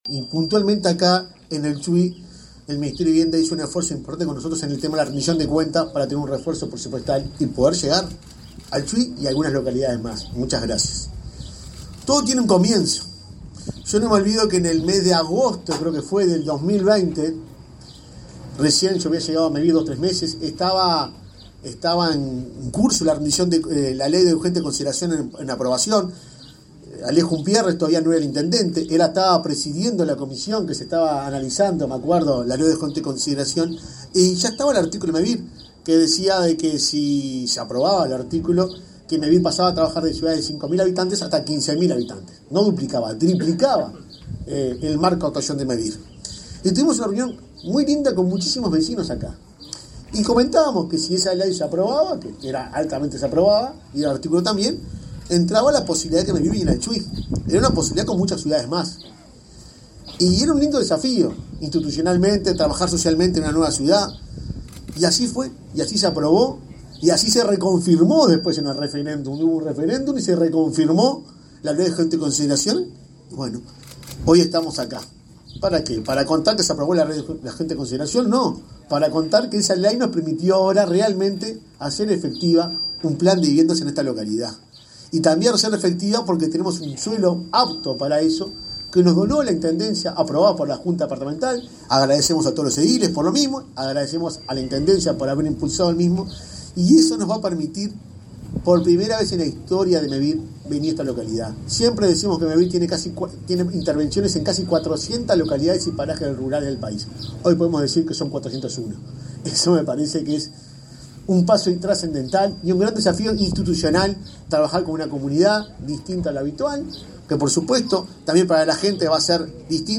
Palabras del presidente de Mevir y el subsecretario de Vivienda
El presidente de Mevir, Juan Pablo Delgado, y el subsecretario de Vivienda, Tabaré Hackenbruch, lanzaron este martes 27 el Plan Mevir en la localidad